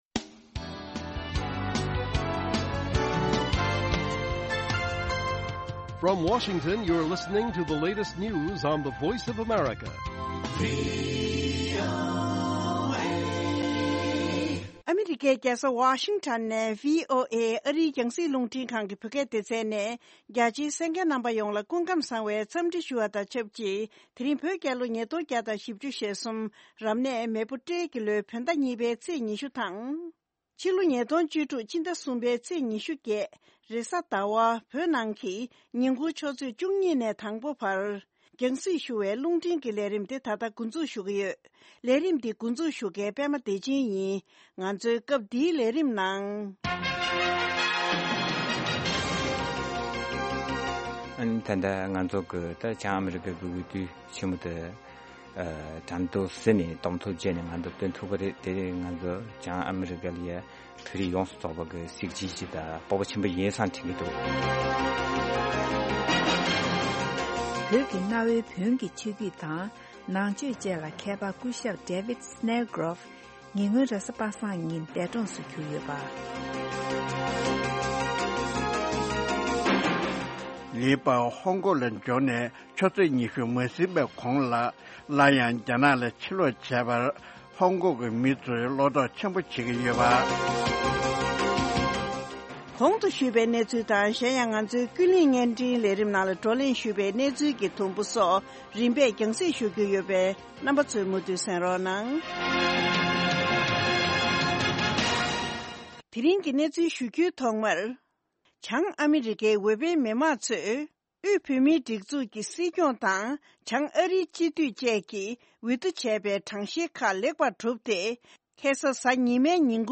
Afternoon News Broadcast daily at 12:00 Noon Tibet time, the Lunchtime Show presents a regional and world news update, followed by a compilation of the best correspondent reports and feature stories from the last two shows. An excellent program for catching up on the latest news and hearing reports and programs you may have missed in the morning or the previous night.